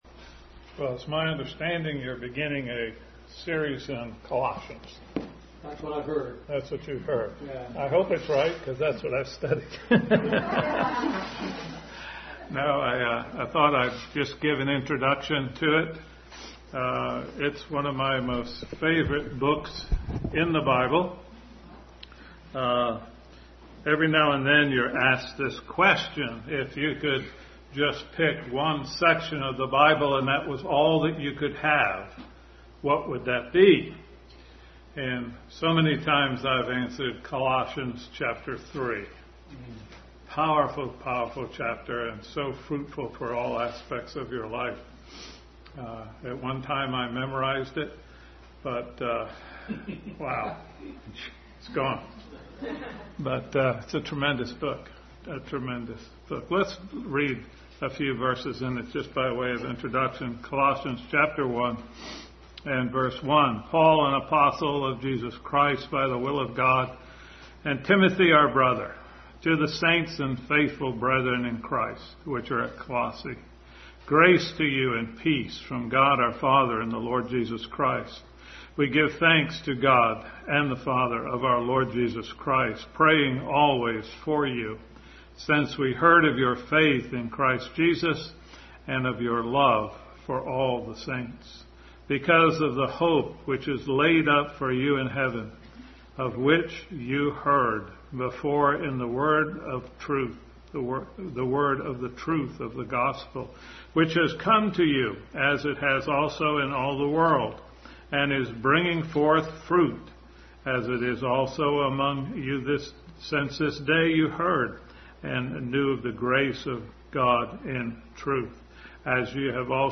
Introduction to Colossians Passage: Colossians 1:1-8, 2:6-8, 4:3-4, 2 Corinthians 13:14 Service Type: Sunday School